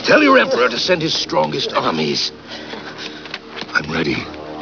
Voice: Miguel Ferrer